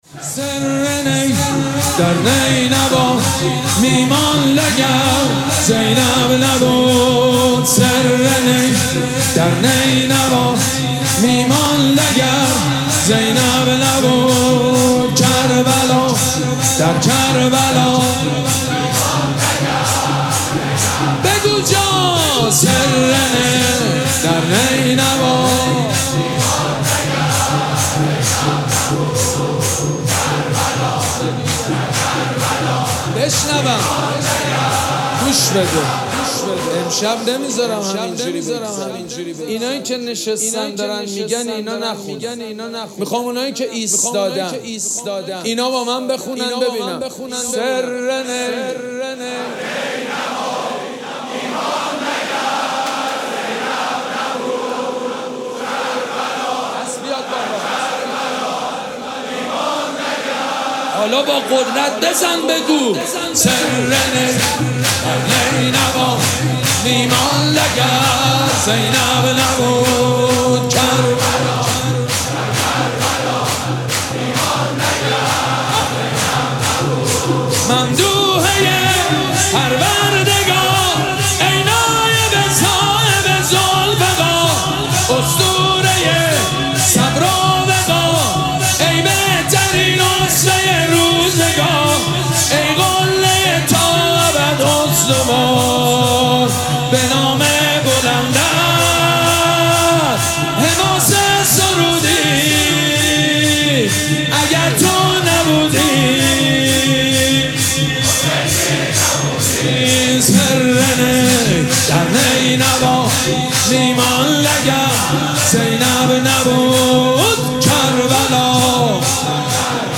مراسم عزاداری شام غریبان محرم الحرام ۱۴۴۷
شور
مداح
حاج سید مجید بنی فاطمه